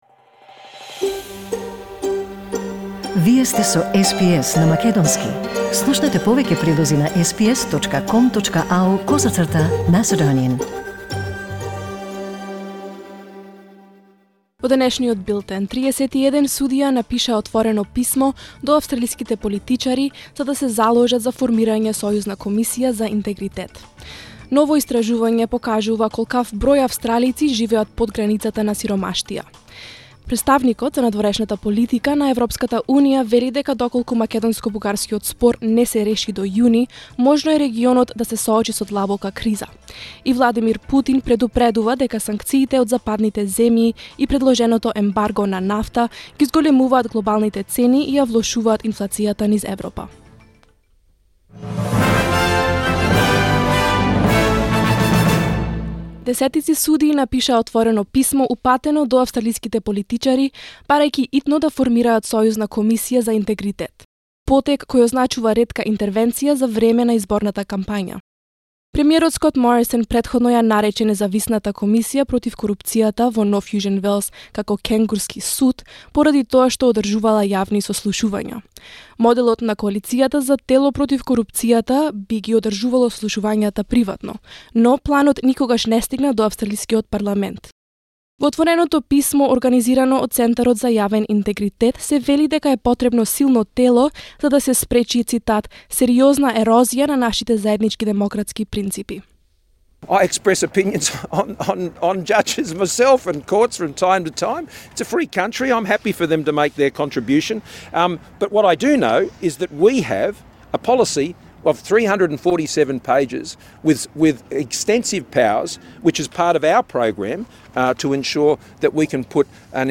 SBS News in Macedonian 18 May 2022